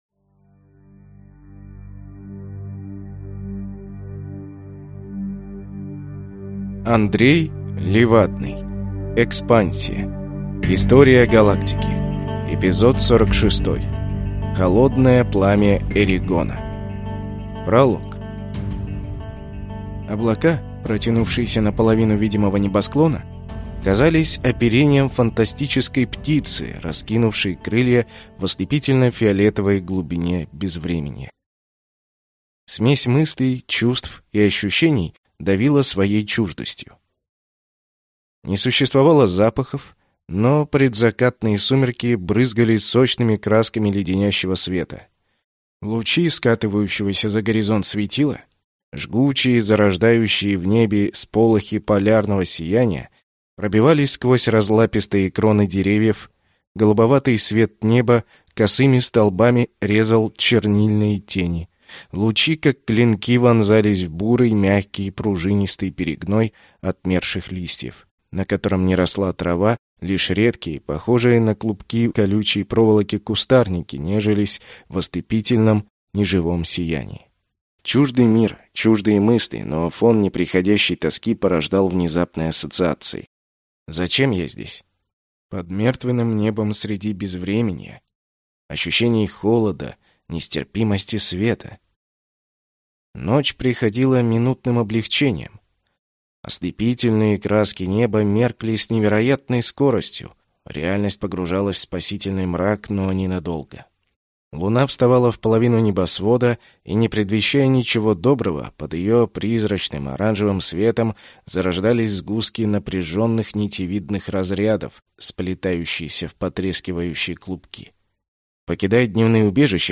Аудиокнига Холодное пламя Эригона | Библиотека аудиокниг